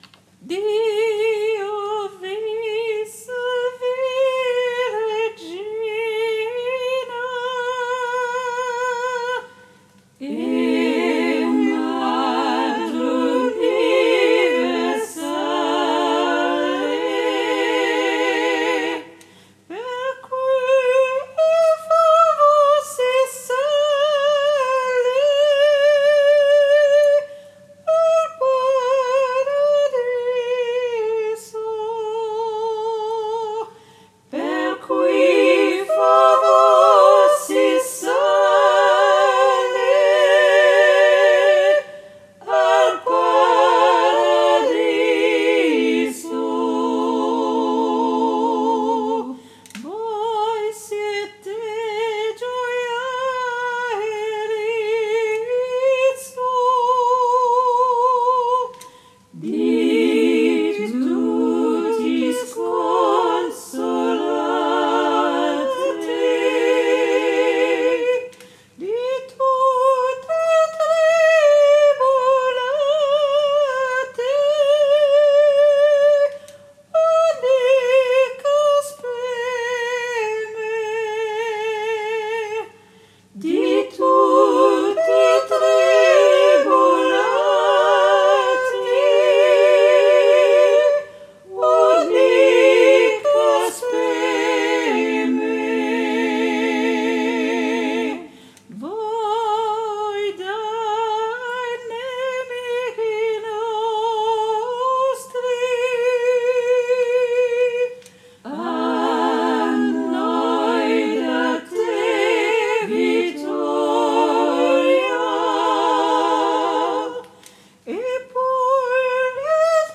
MP3 versions chantées